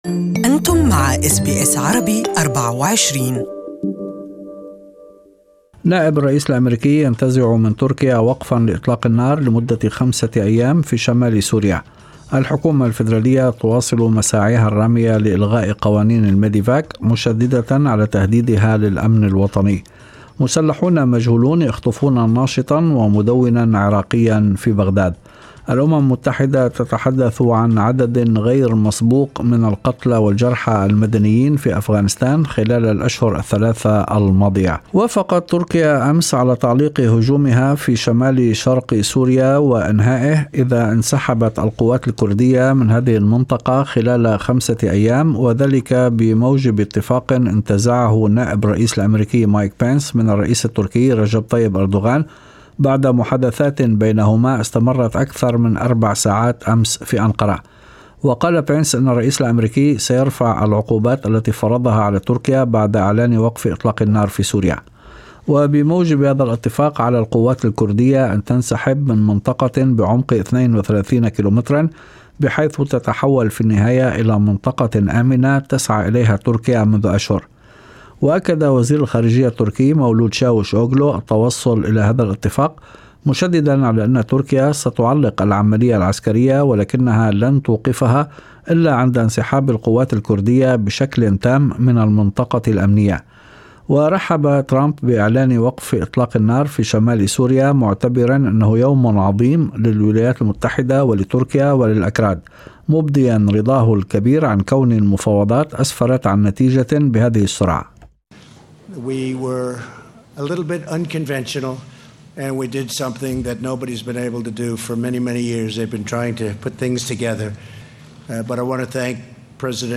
Evening News Bulletin